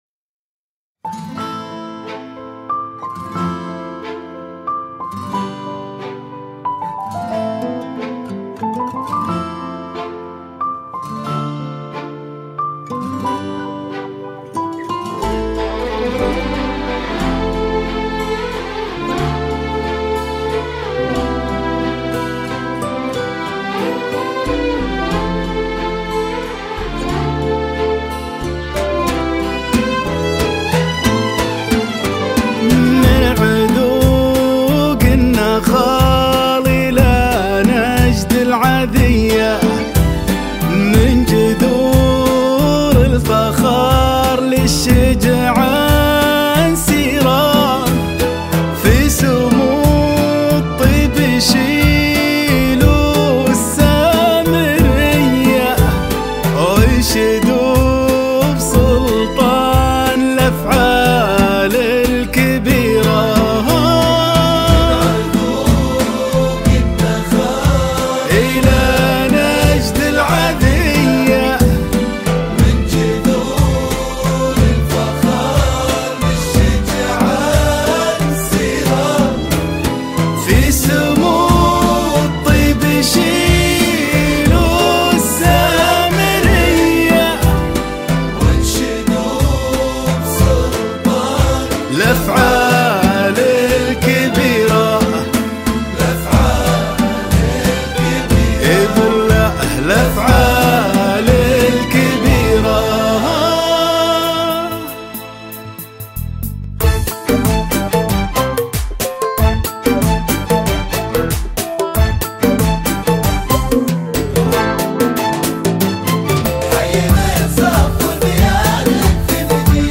زفة معرس
بدون موسيقى